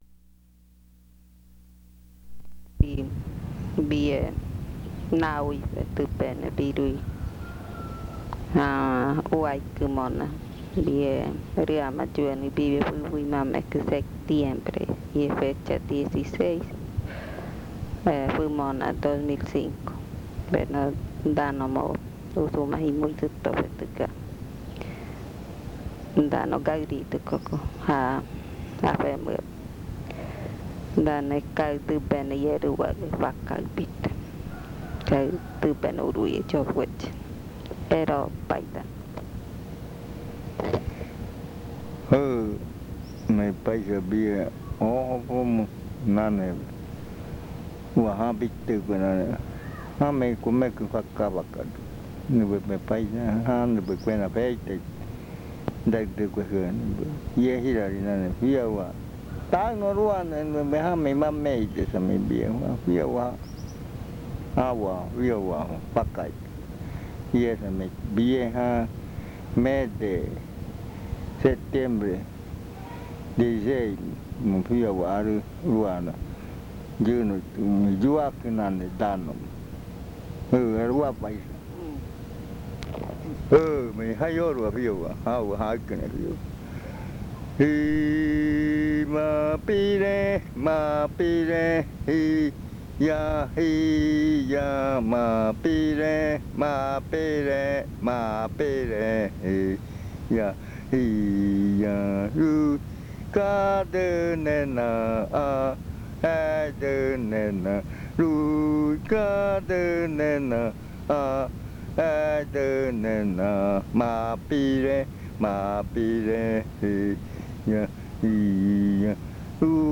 This chant is part of the collection of chants from the Yuakɨ Murui-Muina (fruit ritual) of the Murui people
Cantos de yuakɨ